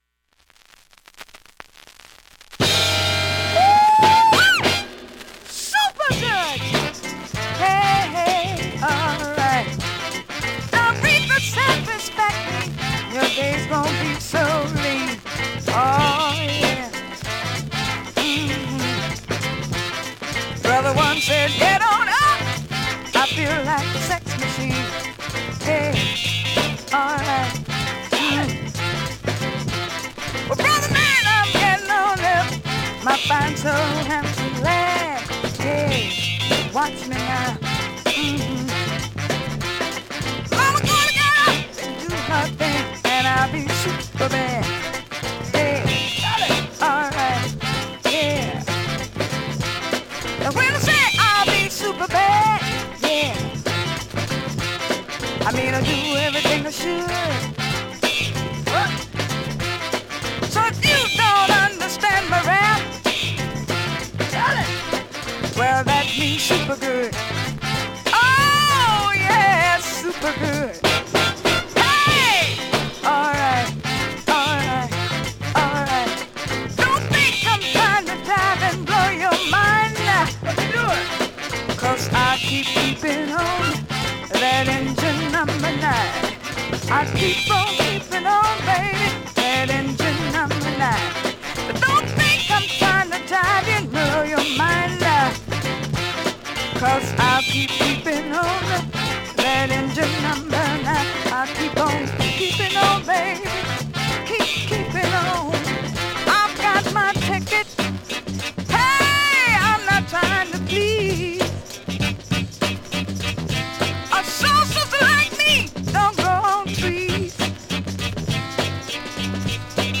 現物の試聴（両面すべて録音時間５分５６秒）できます。